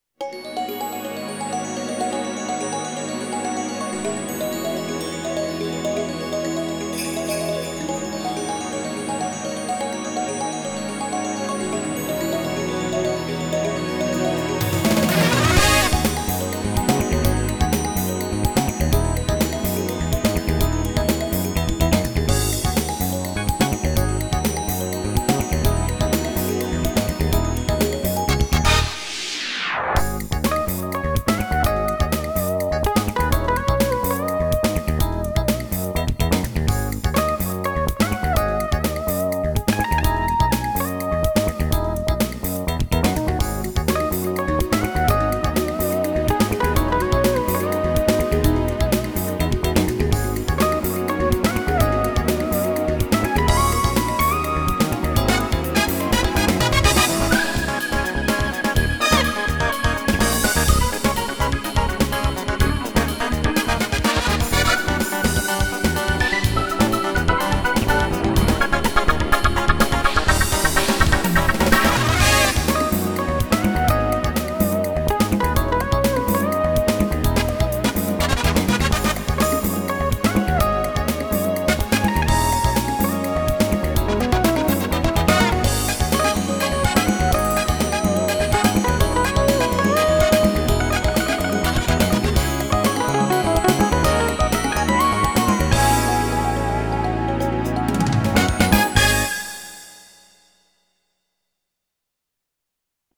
롤랜드 사운드 캔버스 VA (SC-55 맵으로 설정) 데모 MIDI 파일 재생